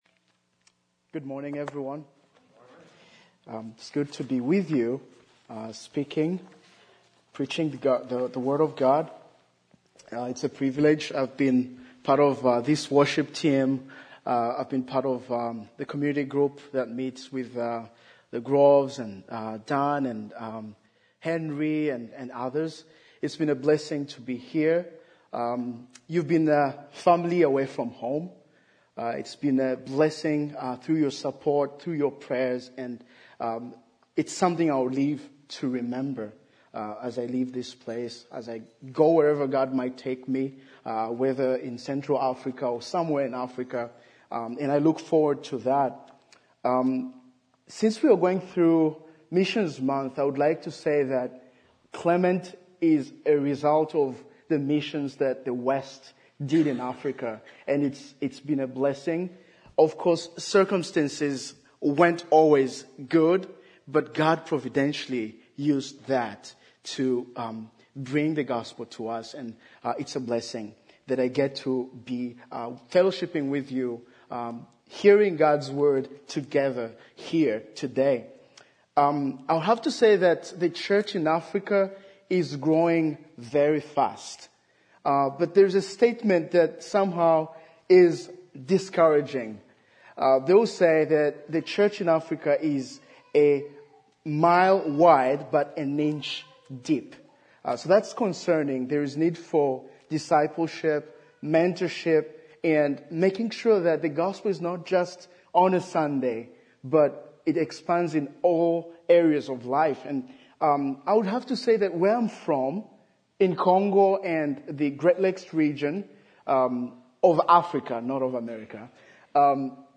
A message from the series "Missions Month."